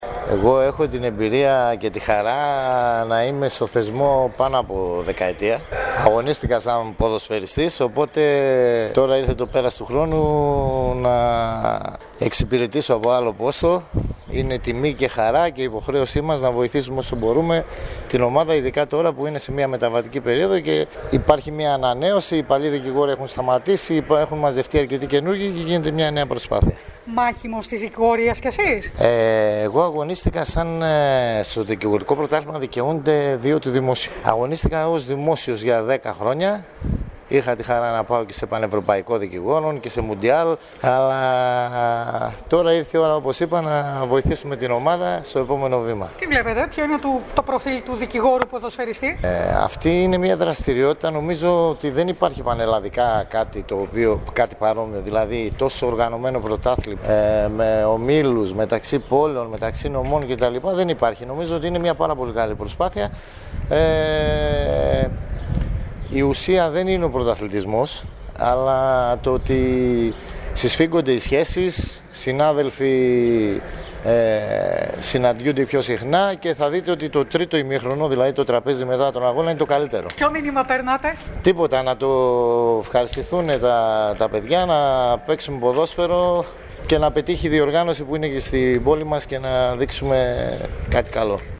«Έχω την εμπειρία και τη χαρά να είμαι πάνω από μια δεκαετία κοντά σε αυτόν το θεσμό», μας είπε και στάθηκε να μιλήσει στην ΕΡΤ Κομοτηνής και στην εκπομπή «Καθημερινές Ιστορίες».